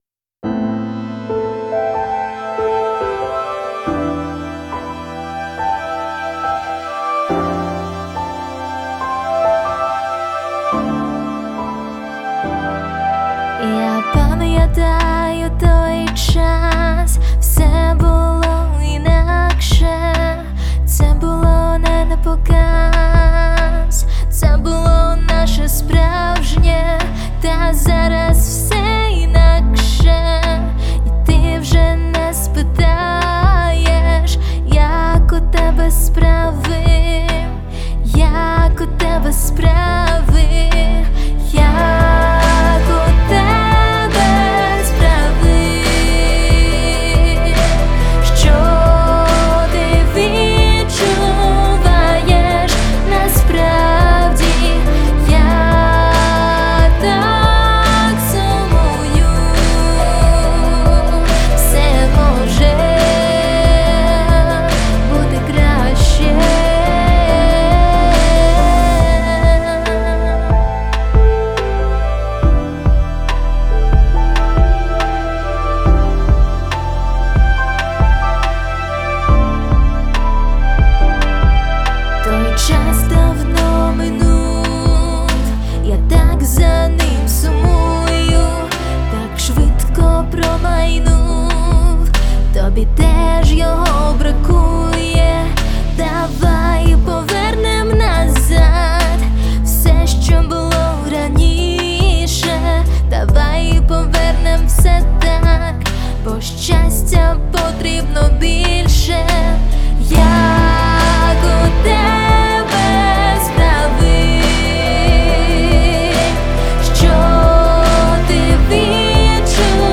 Але новий трек — це ностальгія, туга й спогади про минуле.